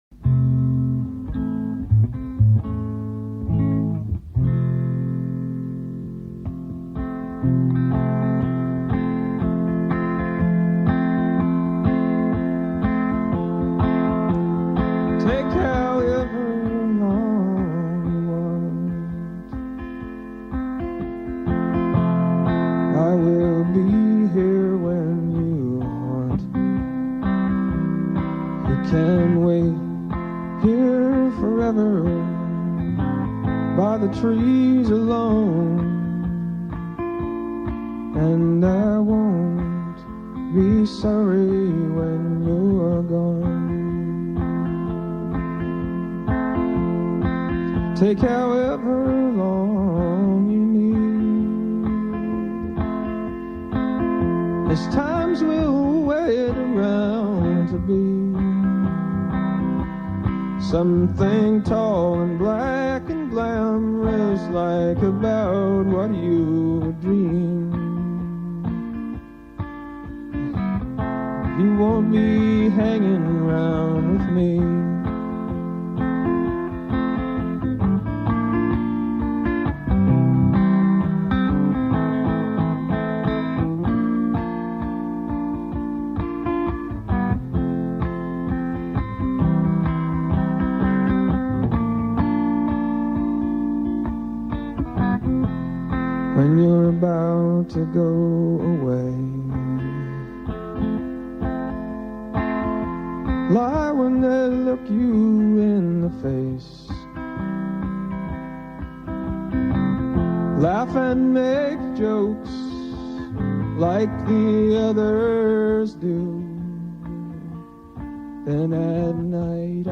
enregistrée le 26/01/1999  au Studio 105